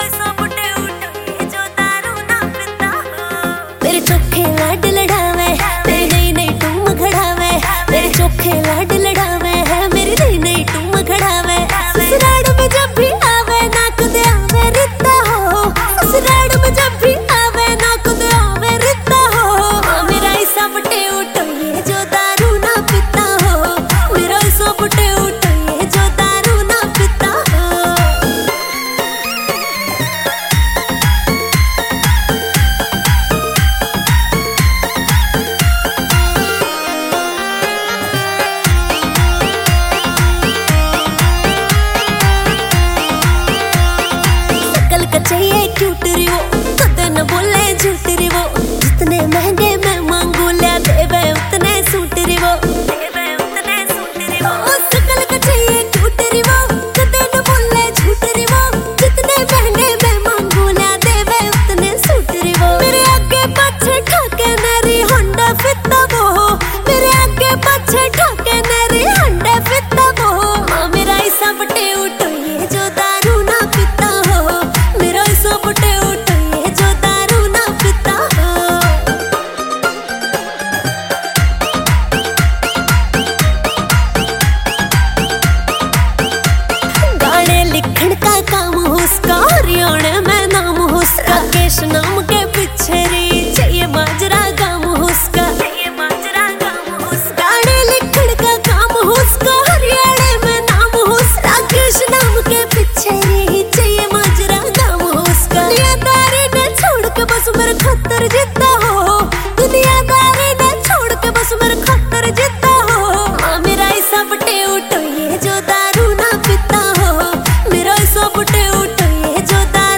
Haryanvi